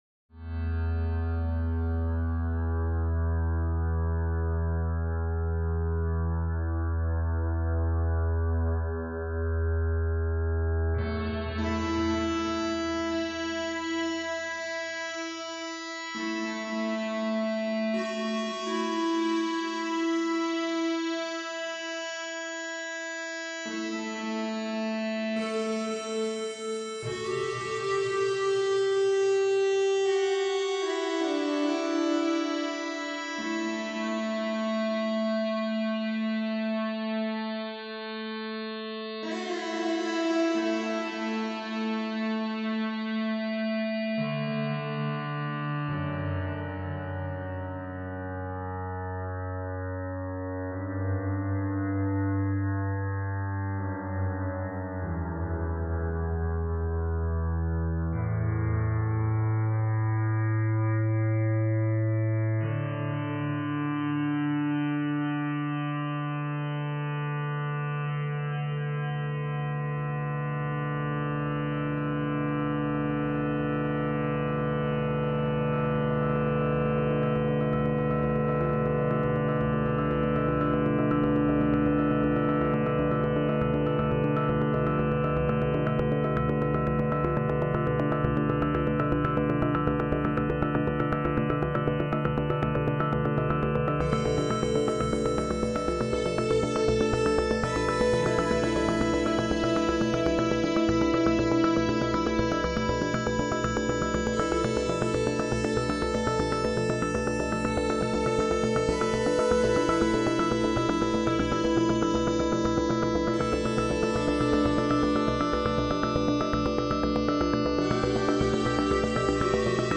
Pitch tracking An electronic piece using a self compositional patch created using the Nord G2 native software.
Note, the Telecaster is not directly heard, only Kyma's oscillator.